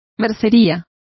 Complete with pronunciation of the translation of haberdashery.